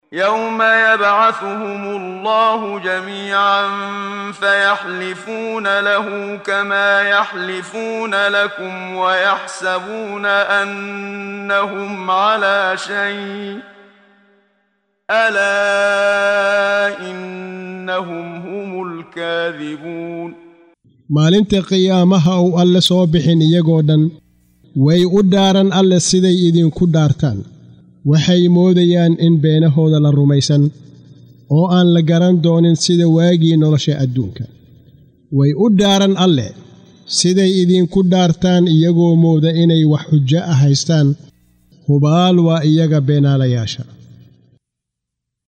Waa Akhrin Codeed Af Soomaali ah ee Macaanida Suuradda Al-Mujaadilah ( doodeysa ) oo u kala Qaybsan Aayado ahaan ayna la Socoto Akhrinta Qaariga Sheekh Muxammad Siddiiq Al-Manshaawi.